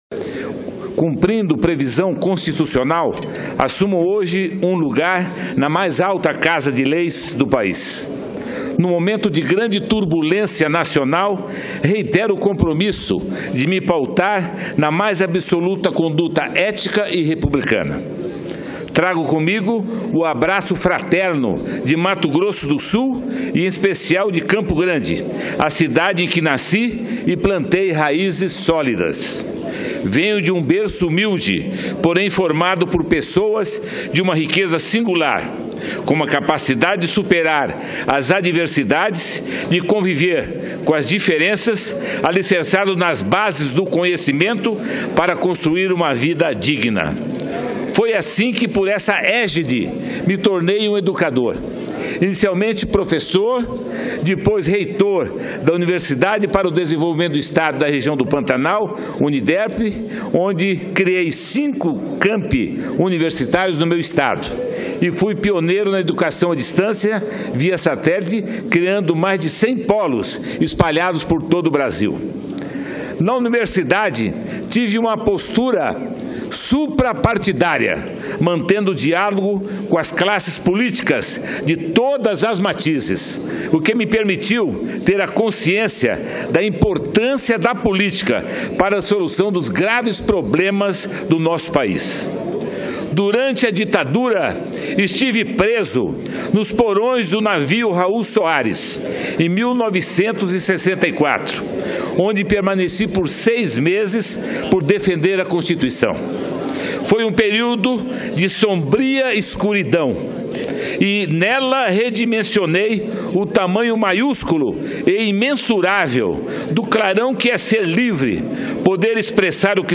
Plenário
Discursos